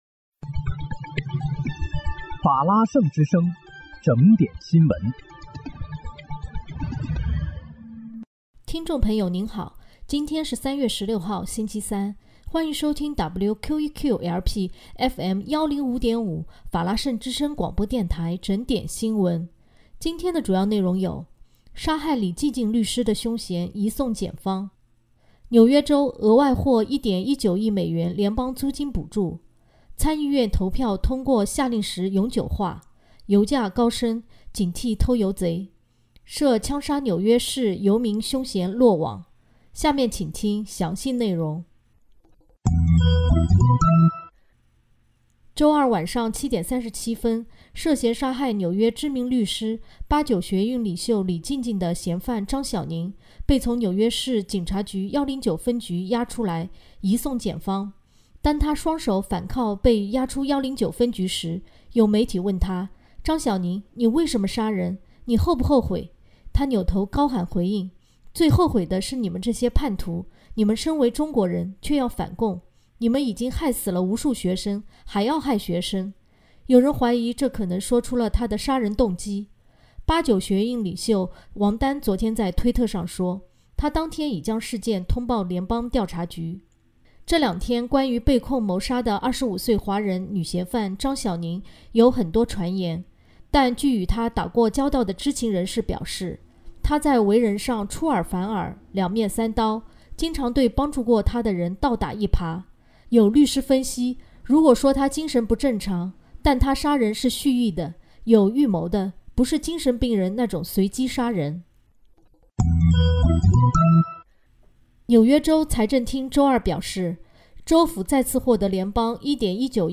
3月16日（星期三）纽约整点新闻
听众朋友您好！今天是3月16号，星期三，欢迎收听WQEQ-LP FM105.5法拉盛之声广播电台整点新闻。